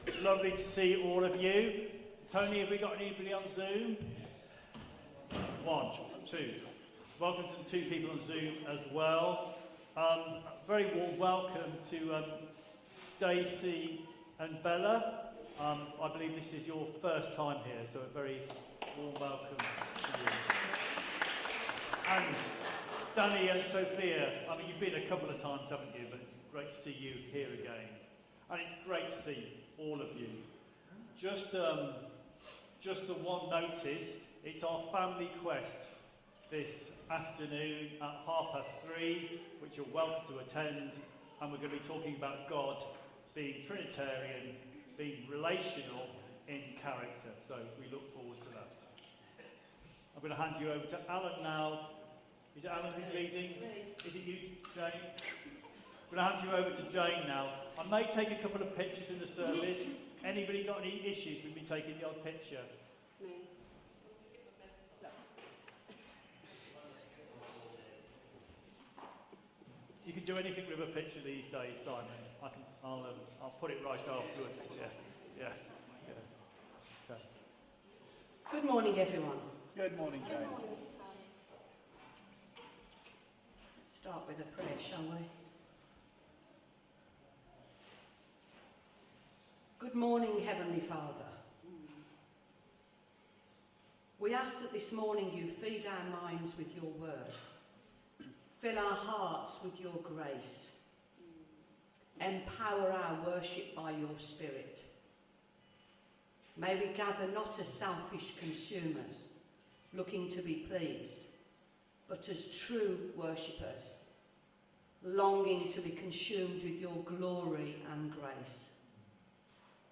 Media for Contemporary Worship on Sun 13th Jul 2025 11:00 Speaker